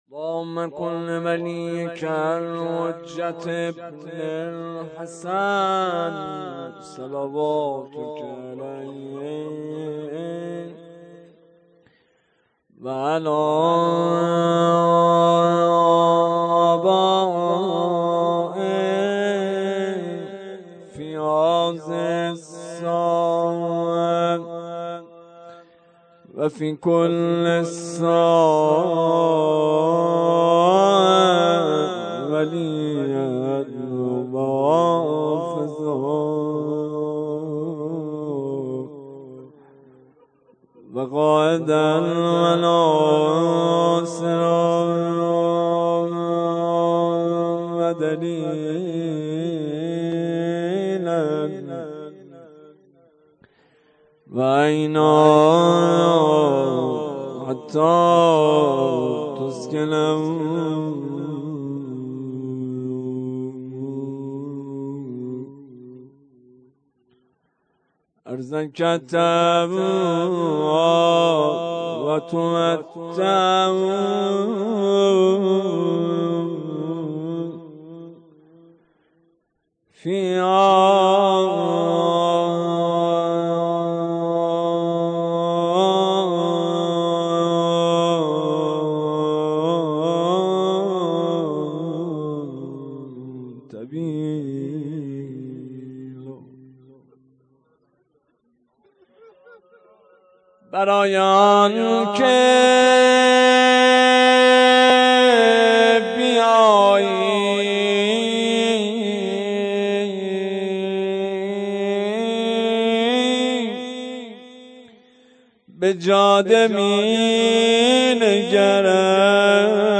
صوت روضه و مداحی در هیئت یا زهرا(س)
سینه زنی زمینه
سینه زنی شور